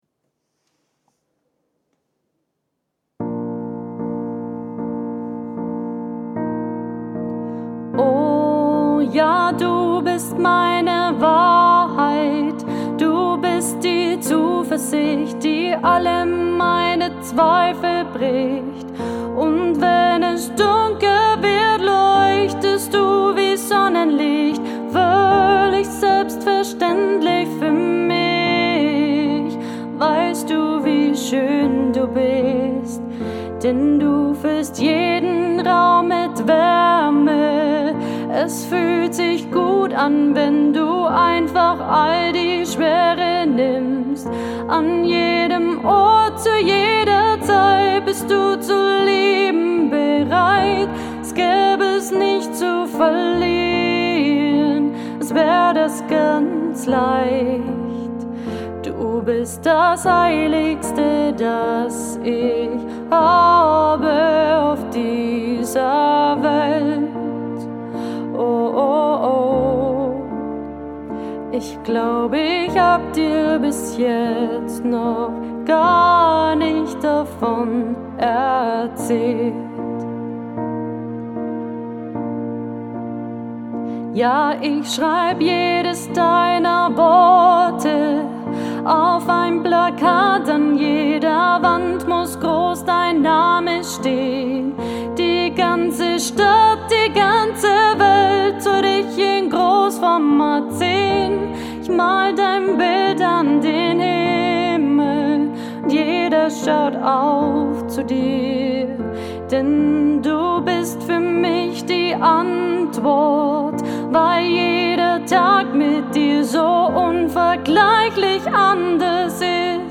romantic Cover Songs